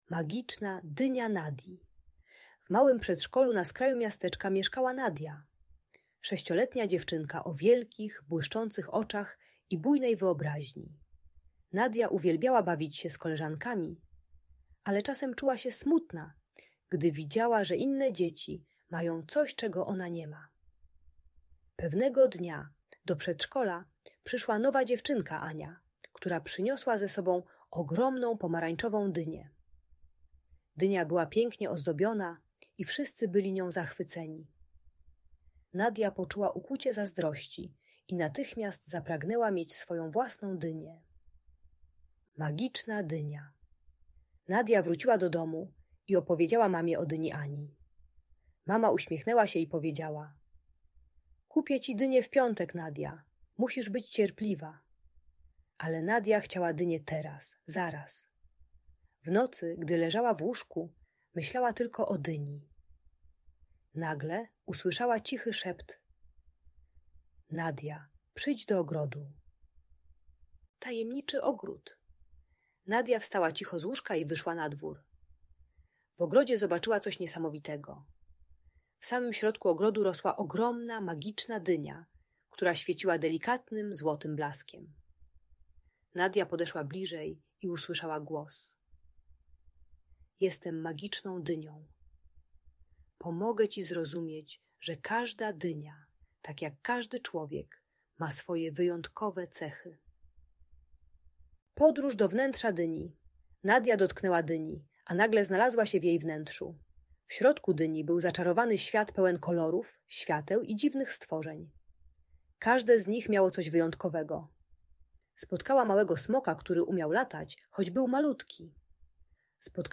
Magiczna Dynia - Niepokojące zachowania | Audiobajka